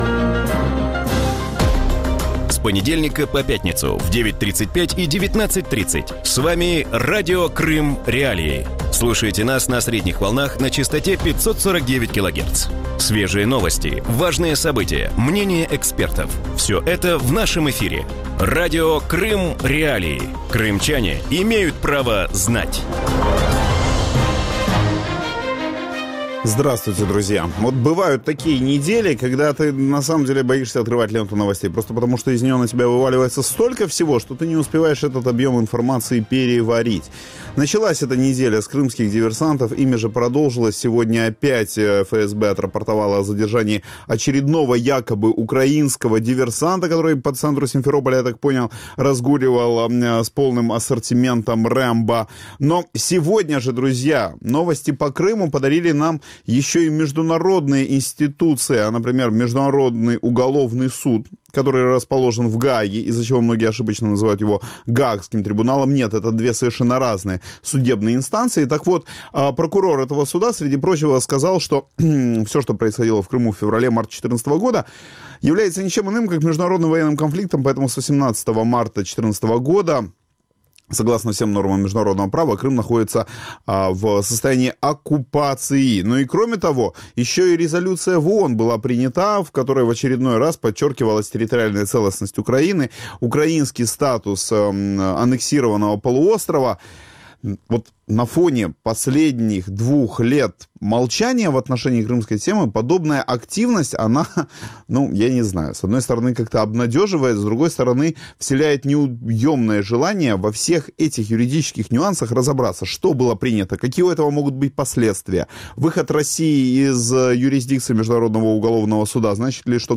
У вечірньому ефірі Радіо Крим.Реалії говорять про звіт прокурора Міжнародного кримінального суду, в якому анексія Криму визнана військовим конфліктом.